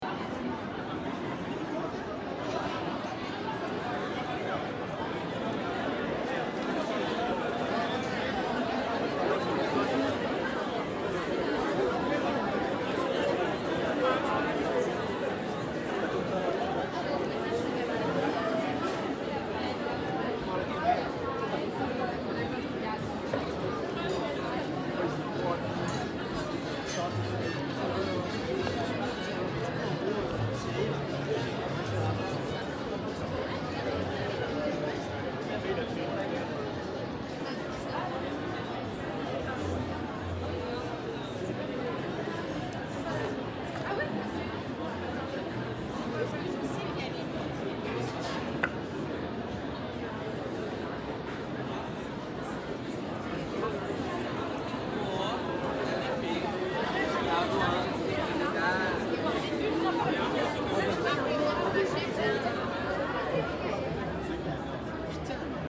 Разговор за столиками в кафе, люди болтают на улице